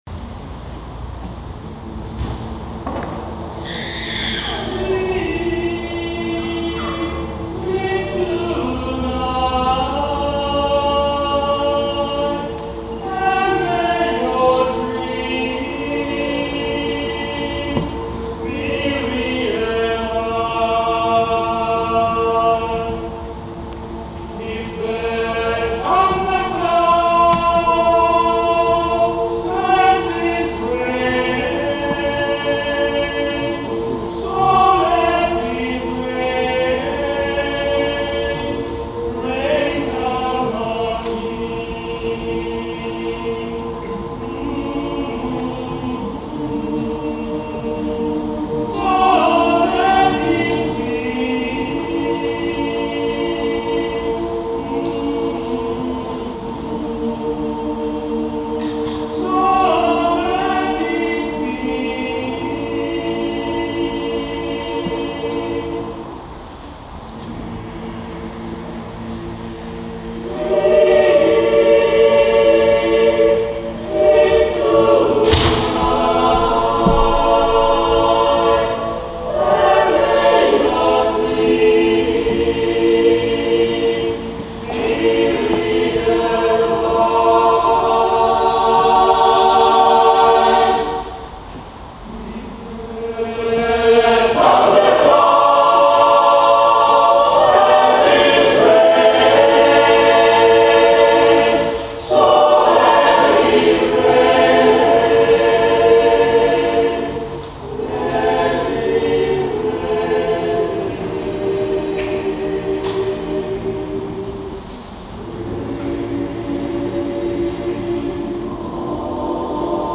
XIV Rassegna corale al tendastrisce
(con sottofondo piovoso)   Pero' mi vuole bene (video) (audio)